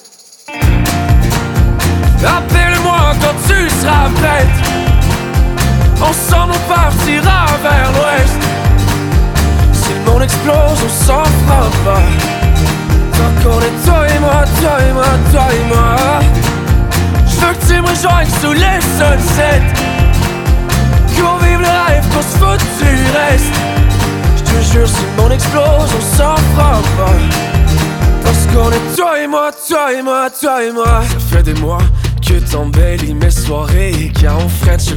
Жанр: Поп музыка / Фолк